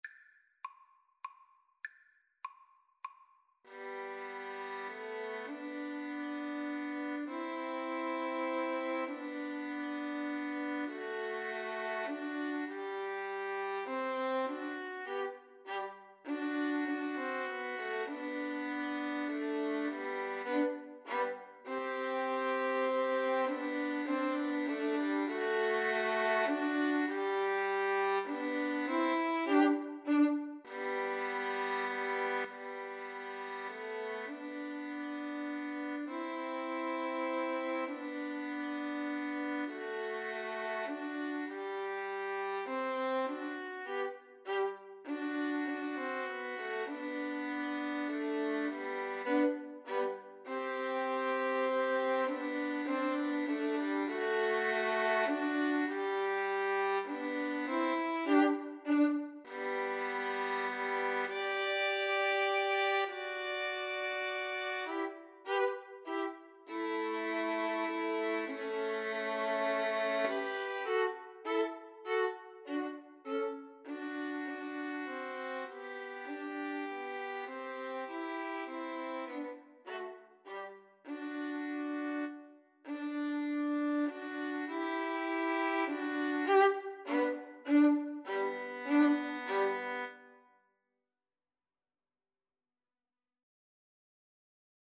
Free Sheet music for Violin Trio
G major (Sounding Pitch) (View more G major Music for Violin Trio )
3/4 (View more 3/4 Music)
Classical (View more Classical Violin Trio Music)